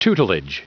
Prononciation du mot tutelage en anglais (fichier audio)
Prononciation du mot : tutelage